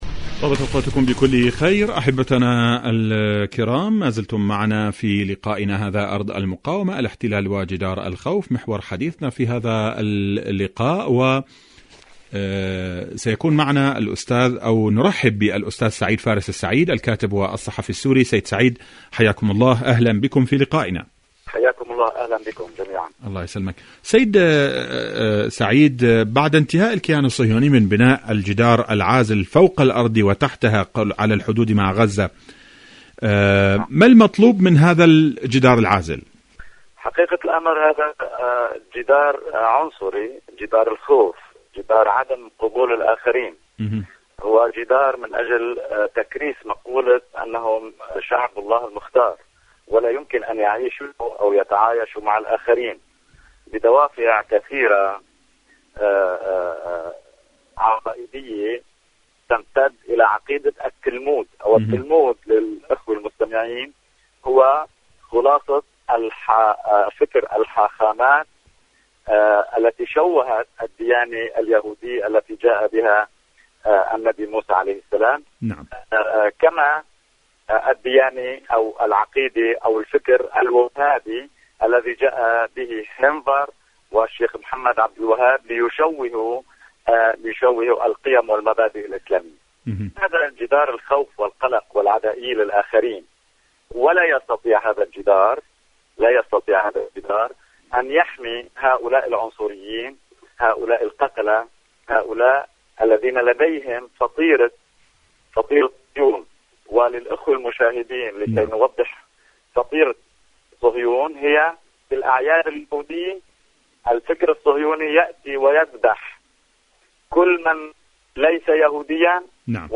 مقابلات برامج إذاعة طهران العربية برنامج ارض المقاومة محور المقاومة مقابلات إذاعية المقاومة كيان الاحتلال جدار الخوف شاركوا هذا الخبر مع أصدقائكم ذات صلة دور العلاج الطبيعي بعد العمليات الجراحية..